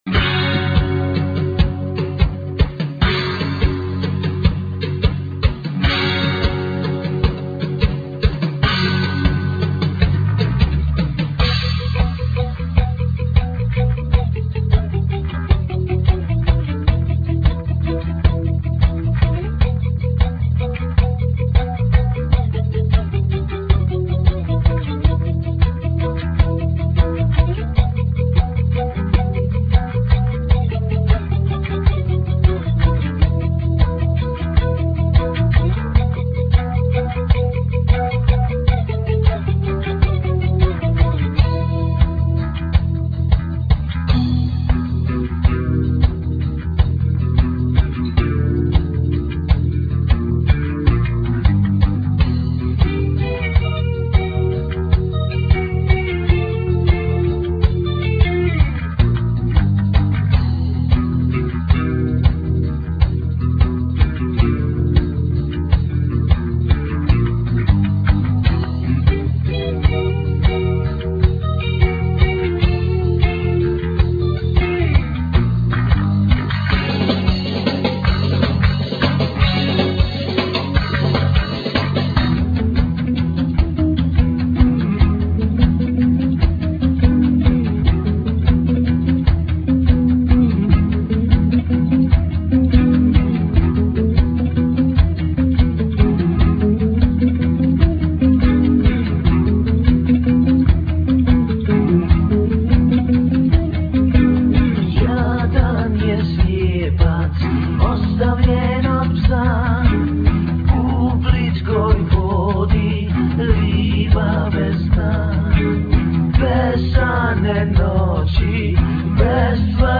bass
guitar
Drums
violin
sax
vocals
percussions